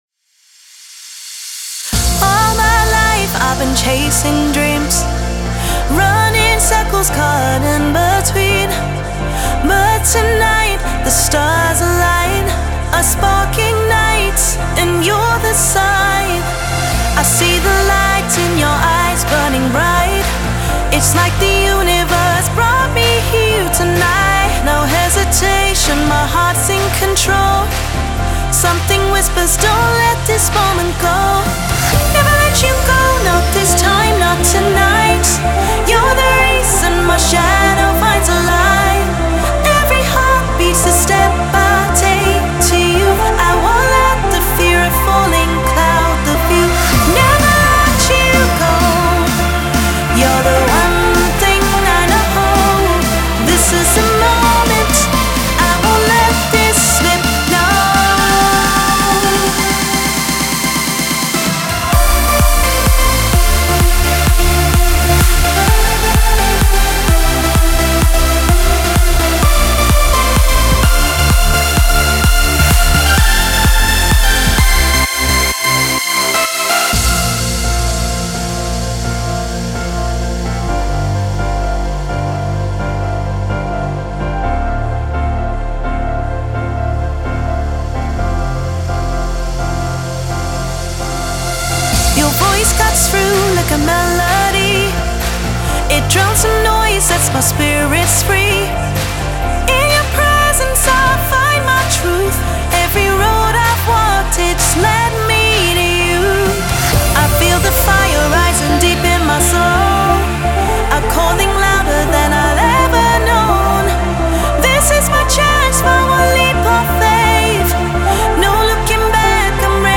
Big Room Electro House Future House House Trance